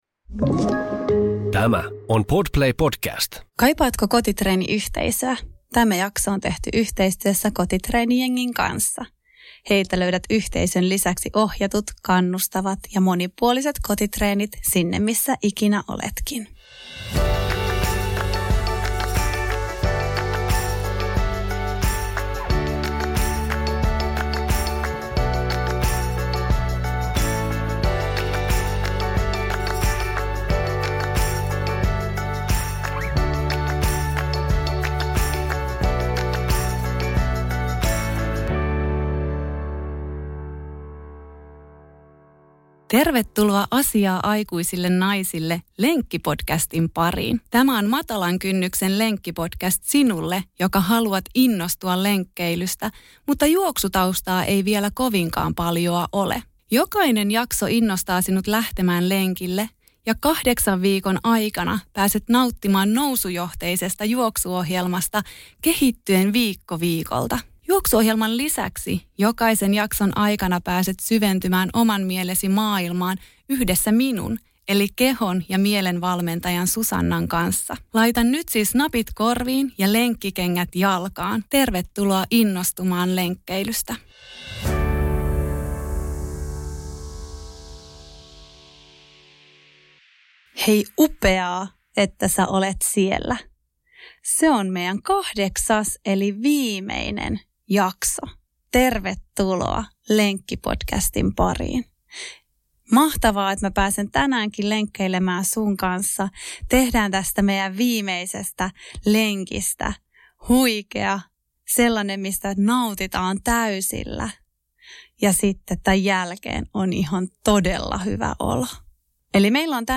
Ensimmäisellä lenkillä aloitettiin minuutin juoksuosuuksista ja nyt juoksemme yhdessä jo 10 minuutin ajan.